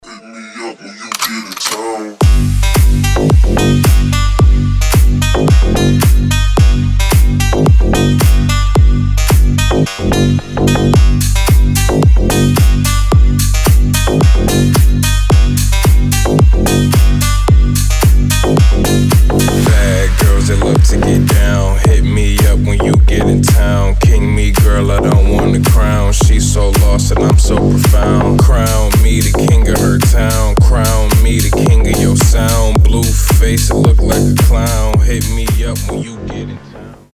• Качество: 320, Stereo
басы
качающие
Gangsta
Стиль: G-house